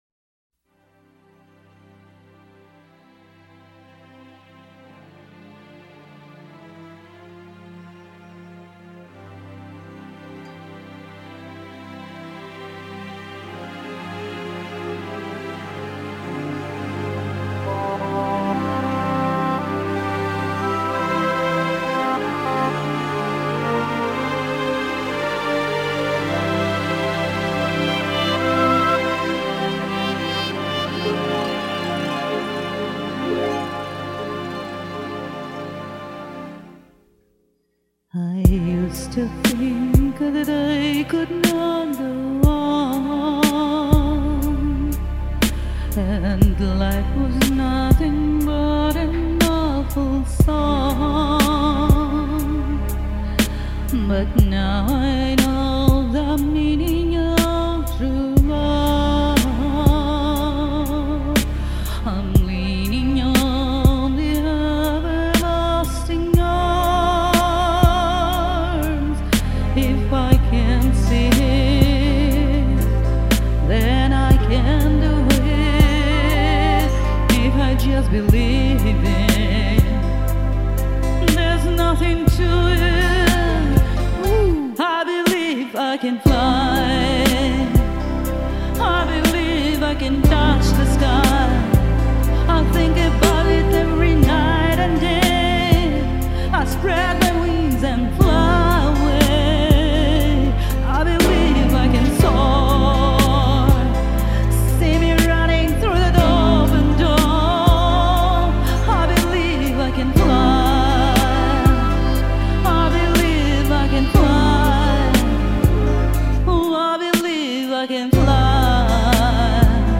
много, кто пел...просто моя версия))